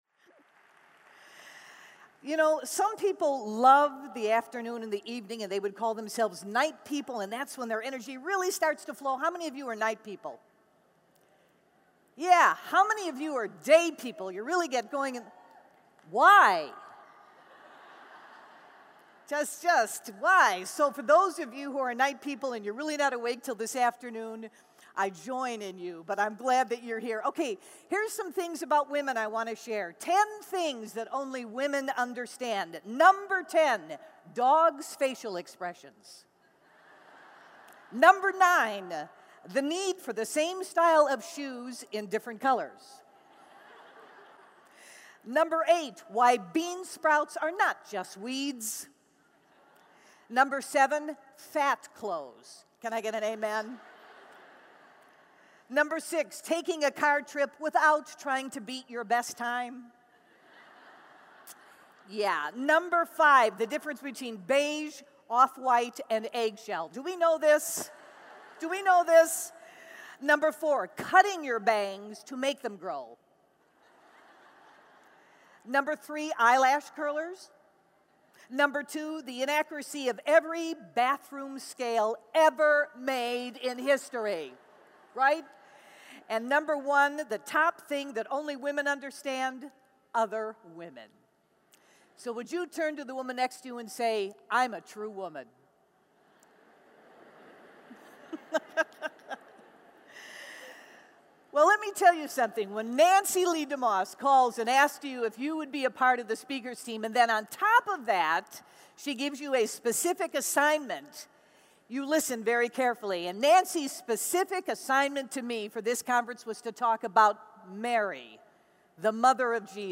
Mary's Secret to Embracing Life-Shaking Circumstances | True Woman '10 Indianapolis | Events | Revive Our Hearts